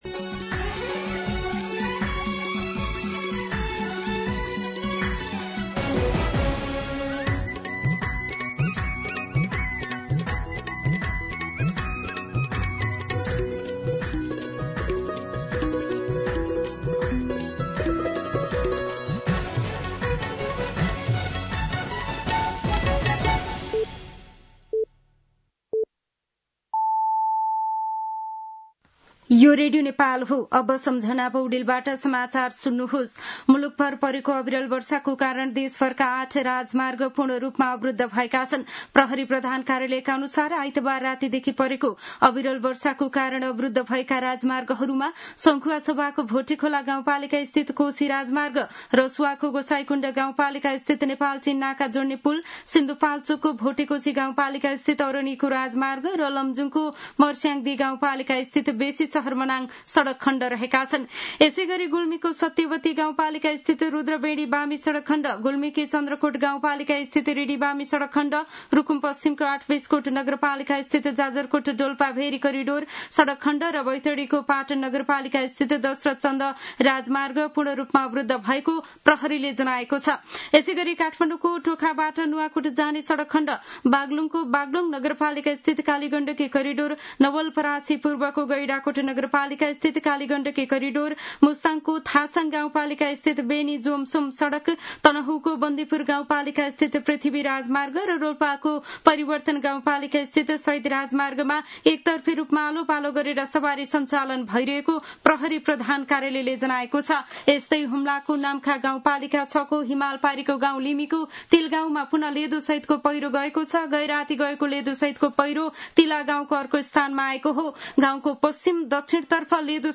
मध्यान्ह १२ बजेको नेपाली समाचार : १३ साउन , २०८२